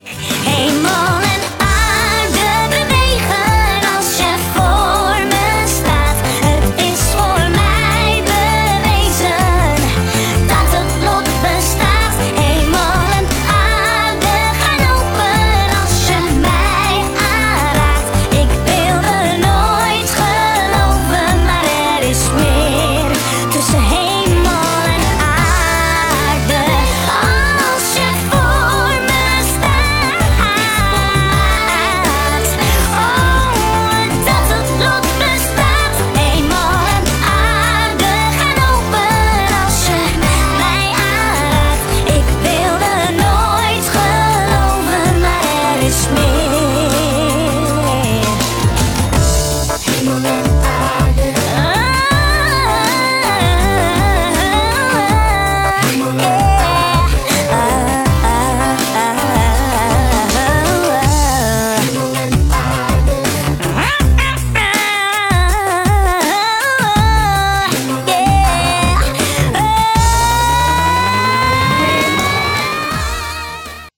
het refrein